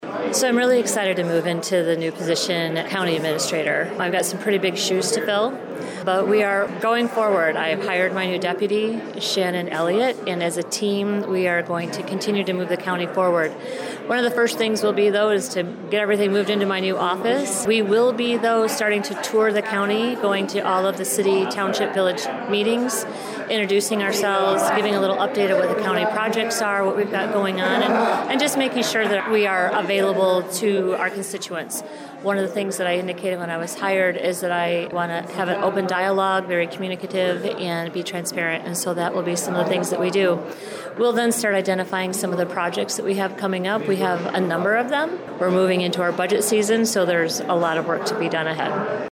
Newly-appointed Administrator Kim Murphy also spoke with WLEN news about some of the first things she will do once it becomes official…